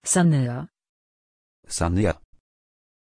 Aussprache von Sanya
pronunciation-sanya-pl.mp3